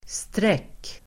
Uttal: [strek:]